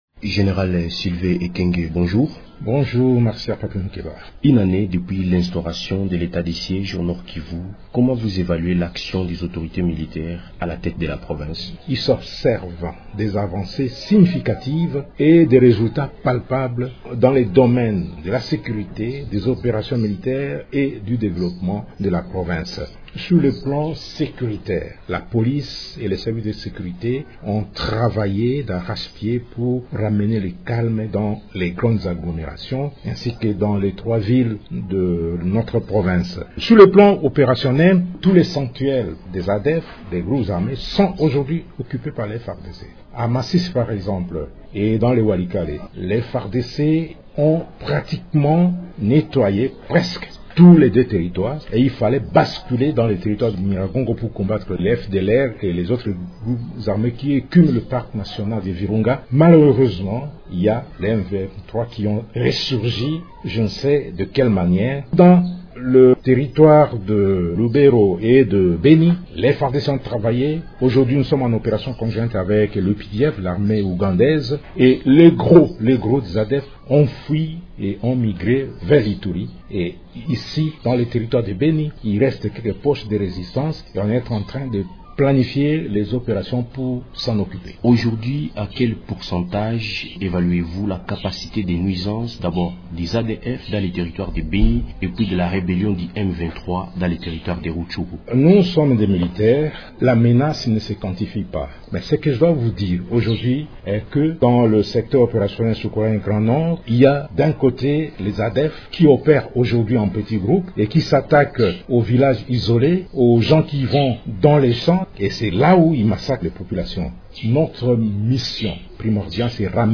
Invité de Radio Okapi, le général Sylvain Ekenge se réjouit des résultats palpables enregistrés dans le domaine de la sécurité, des opérations militaires et de développement, au Nord-Kivu.